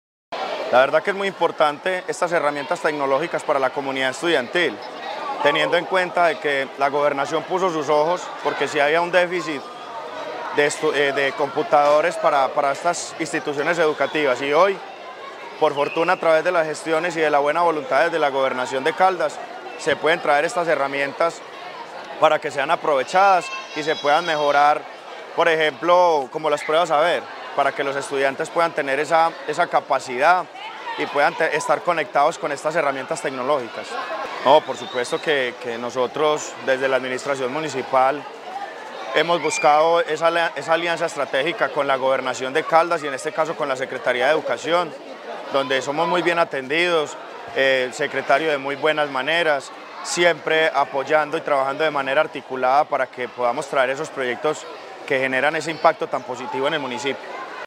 Alcalde de Marmato, Carlos Cortés Chavarriaga.